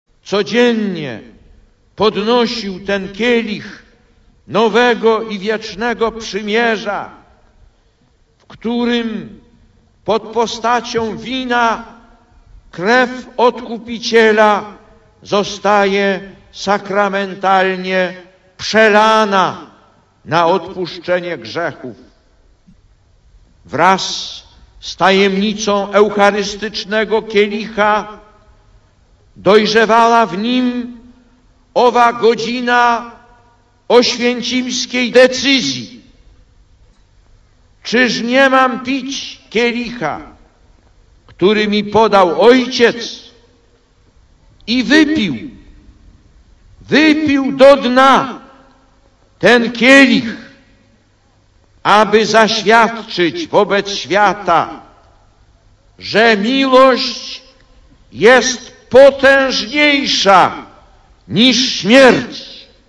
Głos Papieża: (